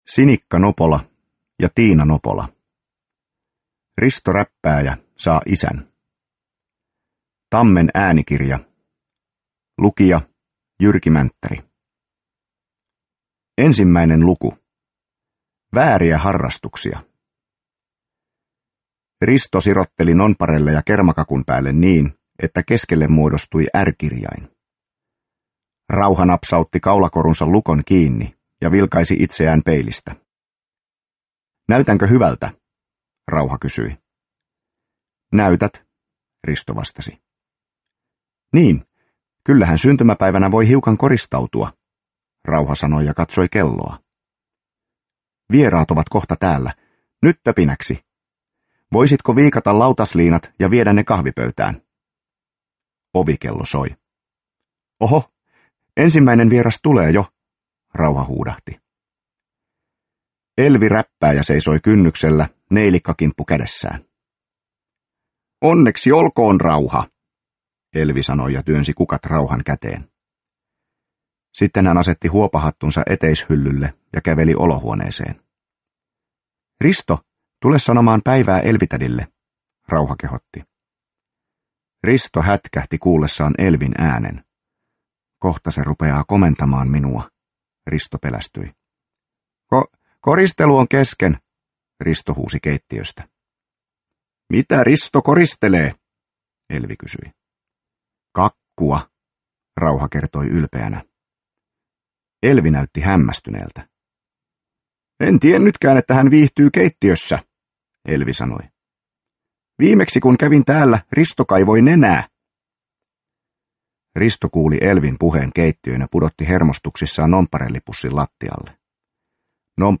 Risto Räppääjä saa isän – Ljudbok – Laddas ner